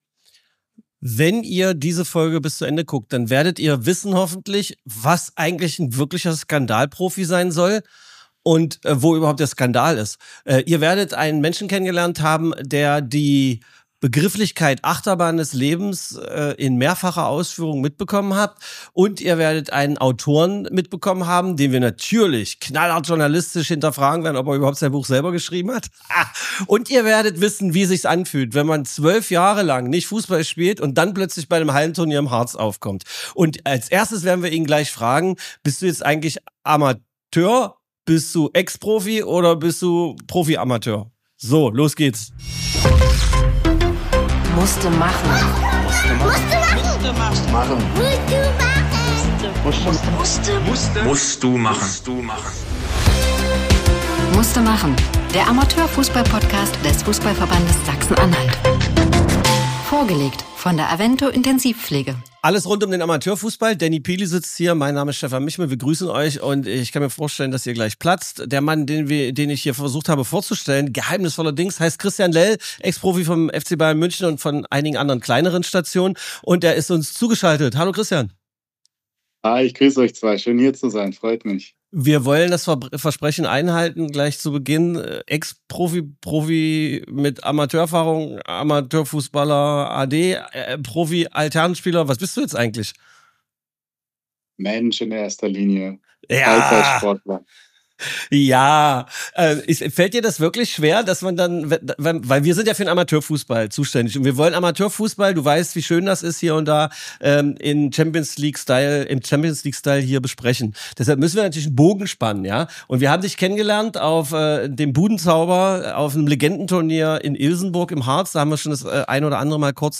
Es geht um den Wahnsinn des Profifußballs, um Besitz ohne Zufriedenheit und um die Frage, was man wirklich braucht, um bei sich selbst anzukommen. Christian Lell erzählt ehrlich, reflektiert und mit vielen lustigen Anekdoten von seinem Weg zu neuen Werten.